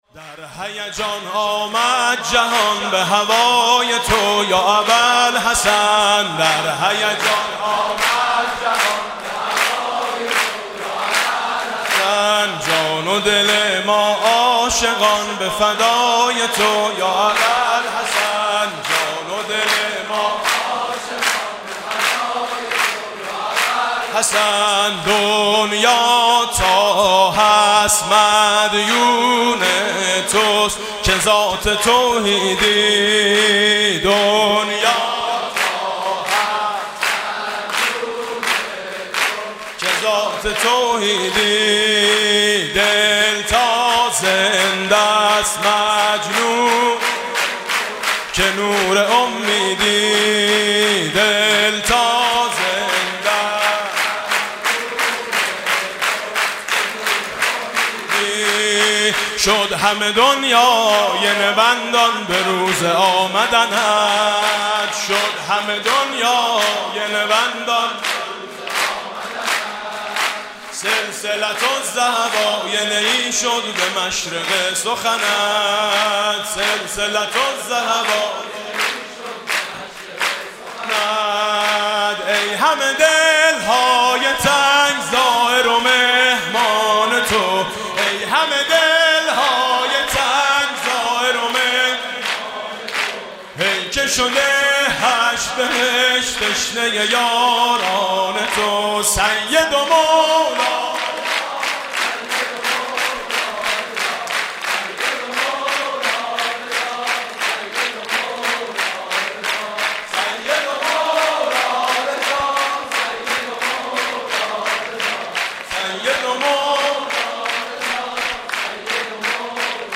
«میلاد امام رضا 1392» سرود: در هیجان آمد به جهان به هوای تو